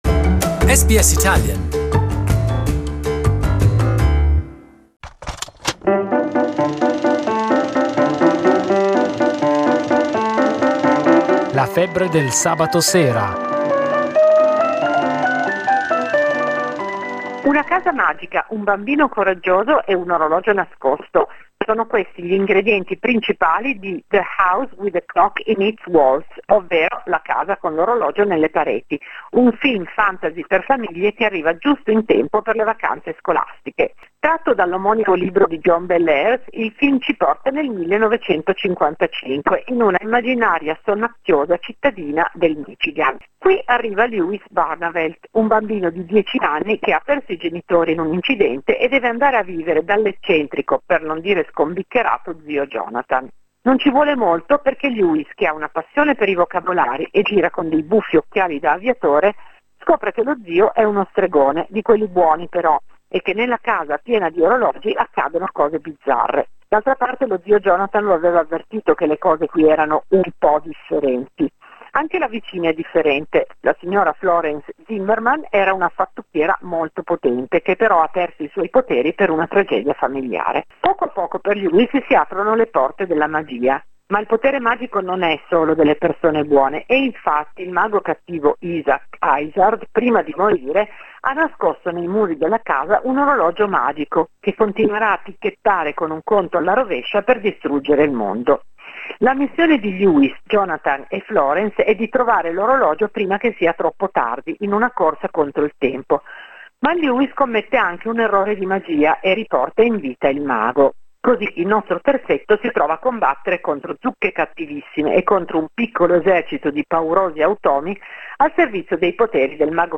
A review